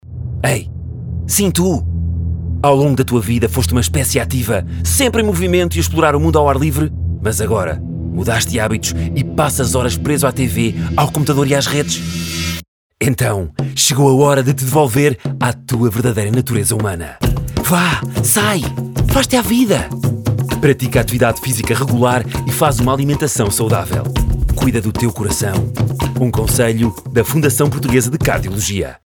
Spot Rádio: